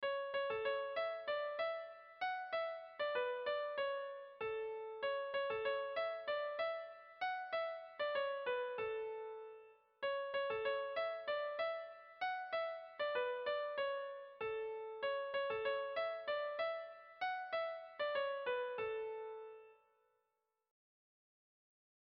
Dantzakoa
Zortziko txikia (hg) / Lau puntuko txikia (ip)
A1A2A1A2